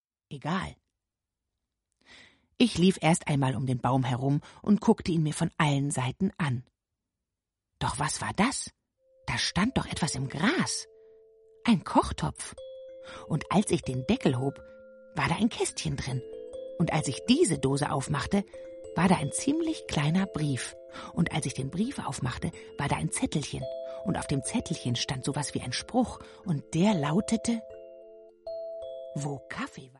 Ravensburger Die fabelhafte Geschichte von Anne Kaffeekanne ✔ tiptoi® Hörbuch ab 3 Jahren ✔ Jetzt online herunterladen!